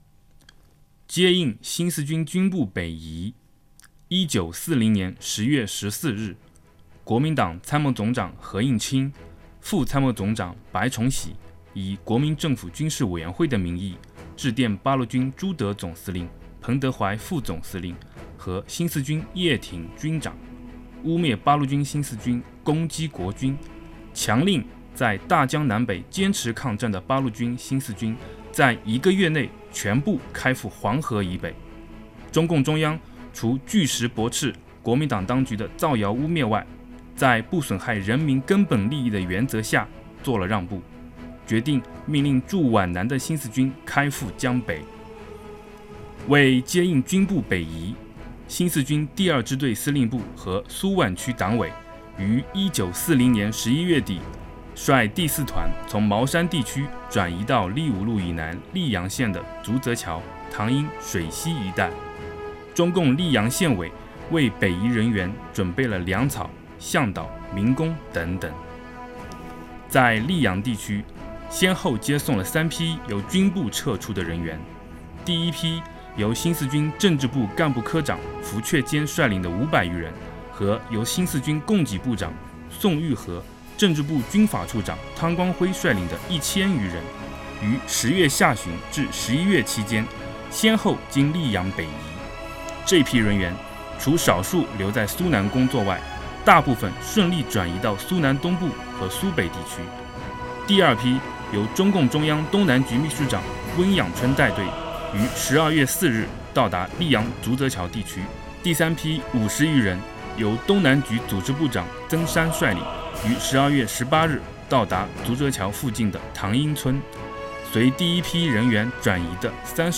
百篇党史·雏鹰诵 | 接应新四军军部北移